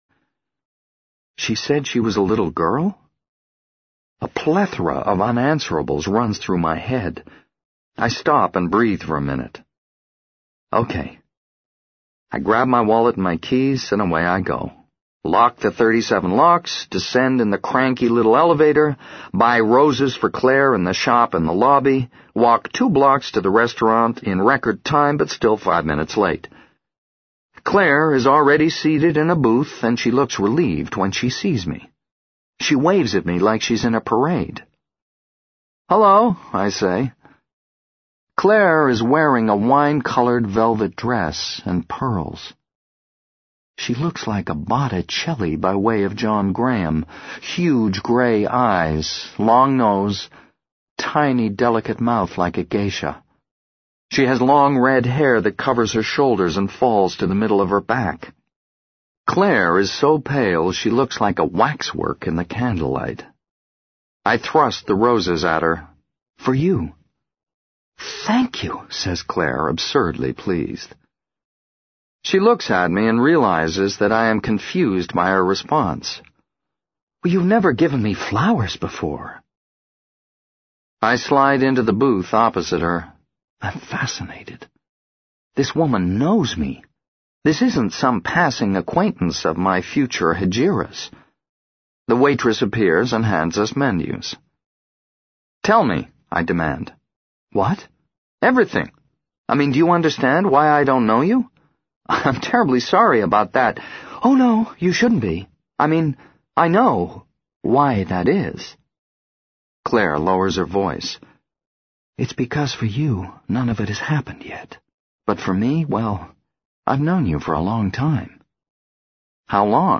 在线英语听力室【时间旅行者的妻子】09的听力文件下载,时间旅行者的妻子—双语有声读物—英语听力—听力教程—在线英语听力室